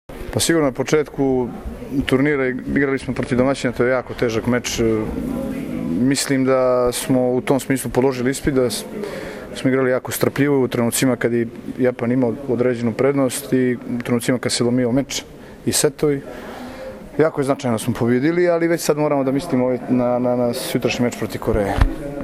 IZJAVA IGORA KOLAKOVIĆA 1